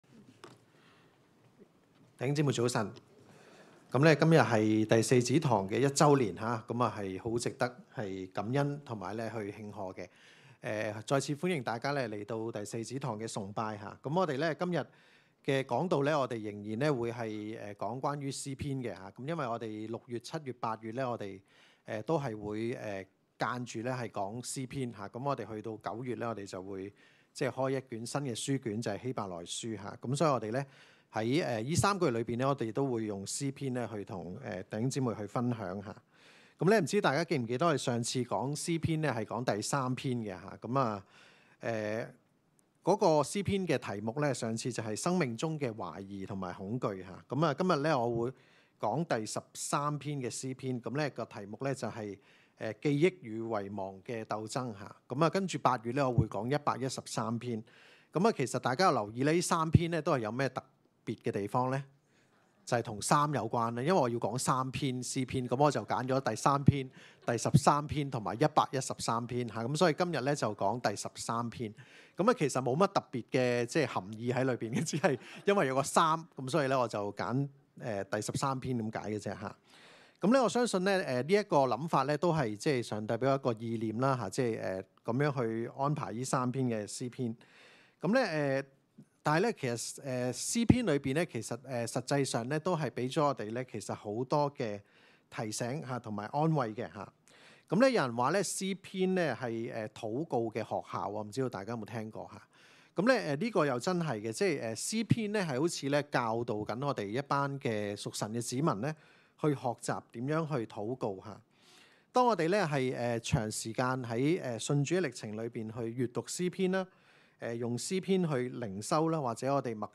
證道重溫